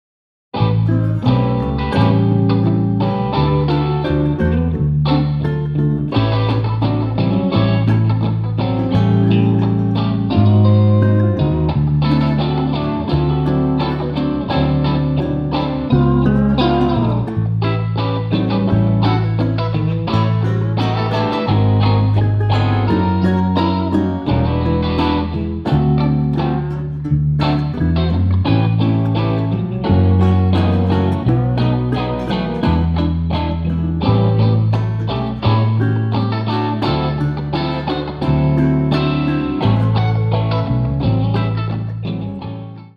Dobro1+2 + RGit1+RGit2 + Bass    Die Dobros habe ich ja nicht zum Spaß eingespielt ... nun also die auch noch dazu gemischt
Dobro_1+2+RGit1+RGit2+Bass.wav